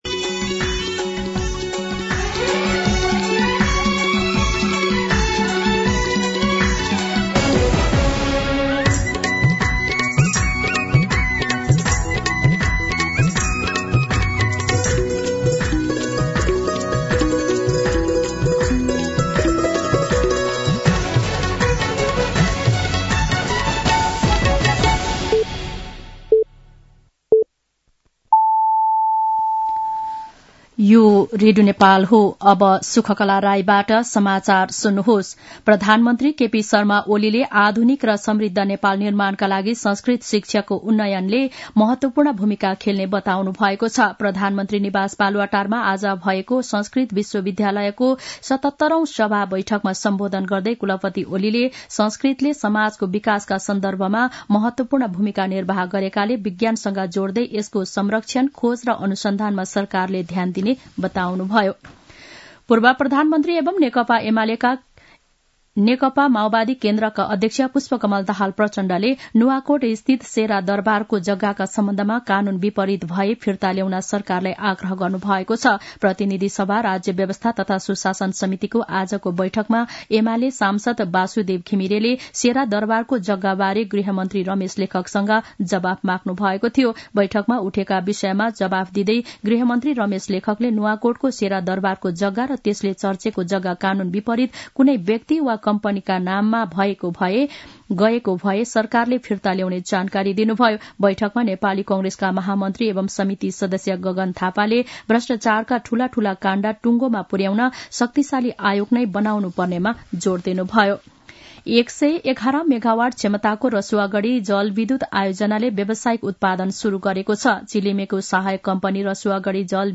दिउँसो ४ बजेको नेपाली समाचार : १९ पुष , २०८१
4-pm-nepali-news.mp3